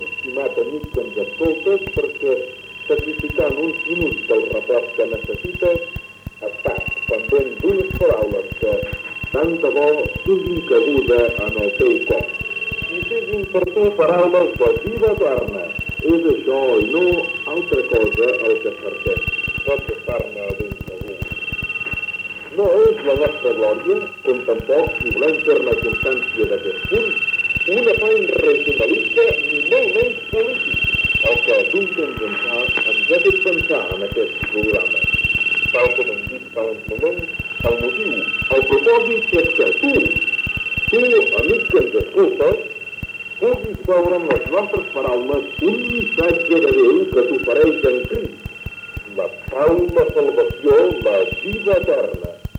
Espai evangèlic en català.
Religió